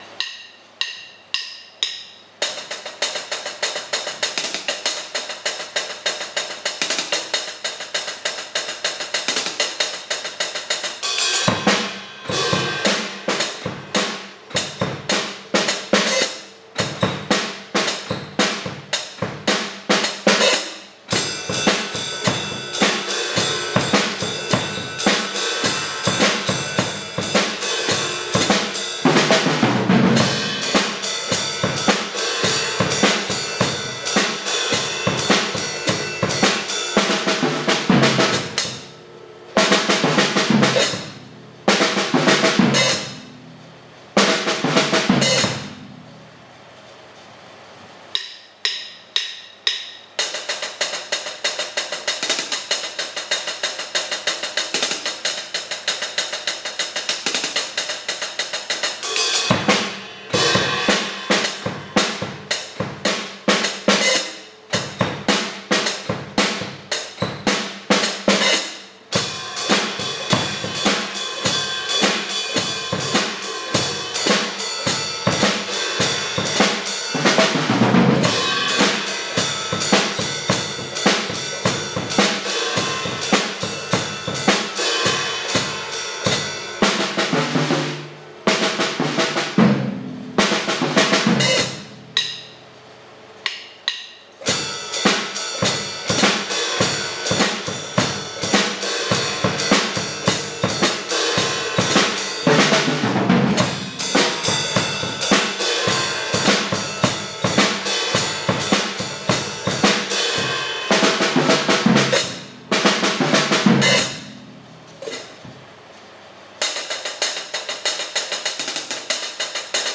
Rough draft for Xmas rock